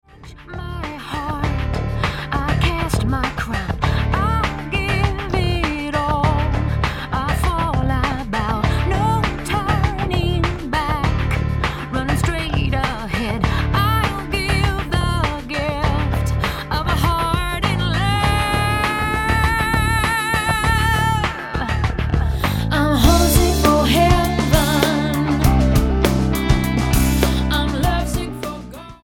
Style: MOR/Soft Pop